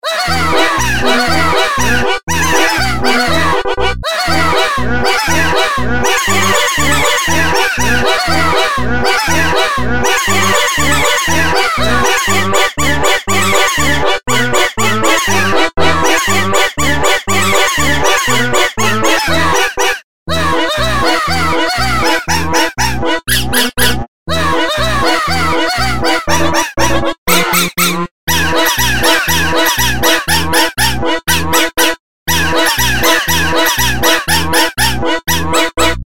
Прикольные рингтоны